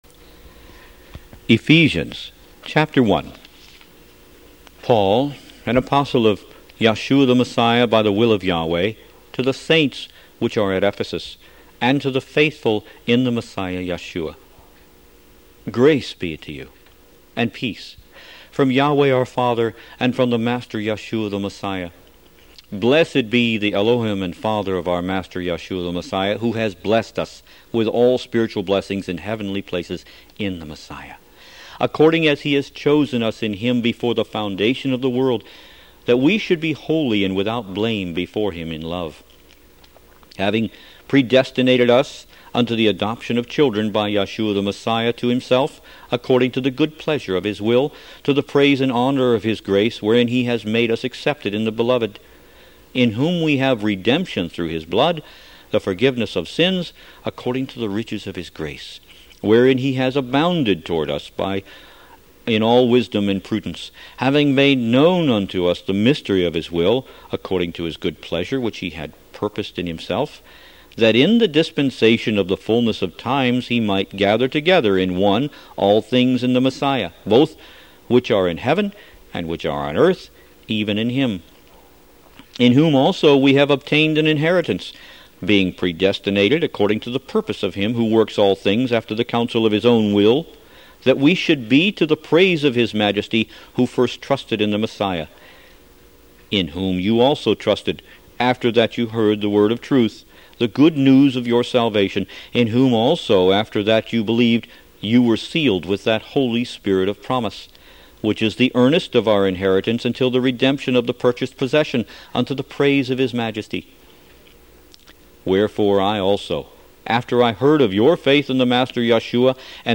Root > BOOKS > Biblical (Books) > Audio Bibles > Messianic Bible - Audiobook > 10 The Book Of Ephesians